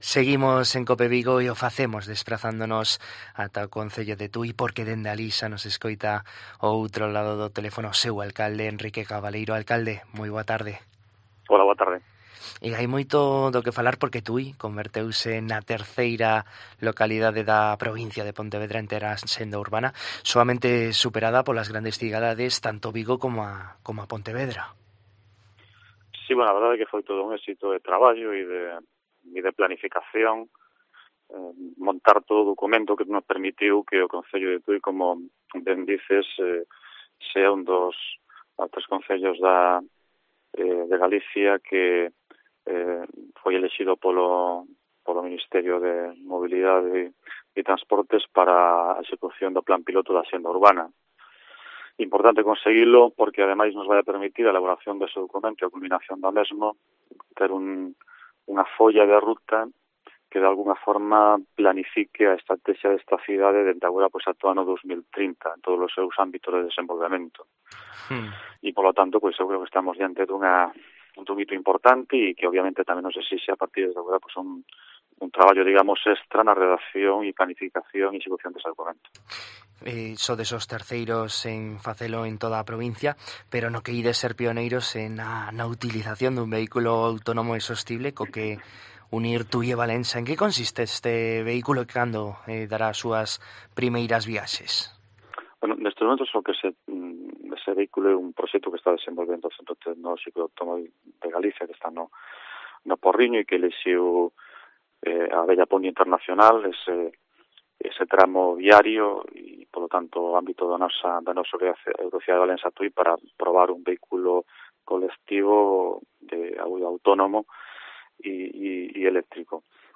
En COPE Vigo falamos co alcalde de Tui, Enrique Cabaleiro, para coñecer a actualidade local deste municipio do sur da provincia de Pontevedra